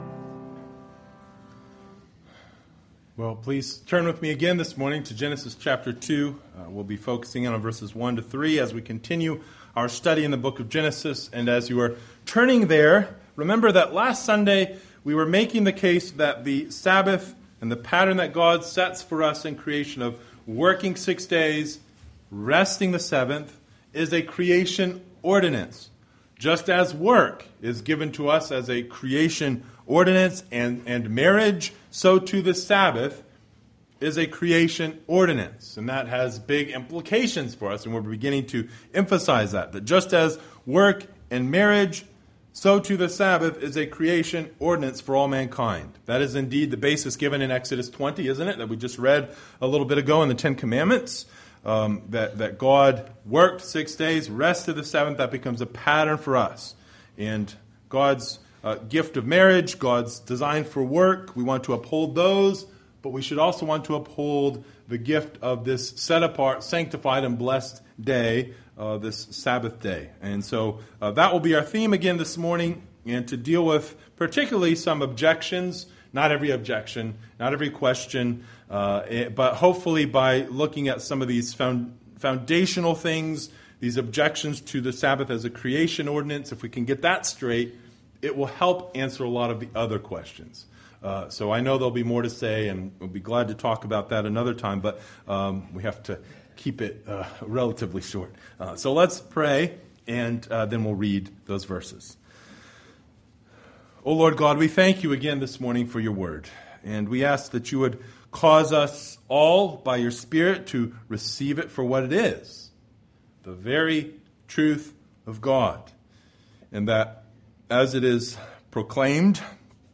Passage: Genesis 1:32 - 2:3 Service Type: Sunday Morning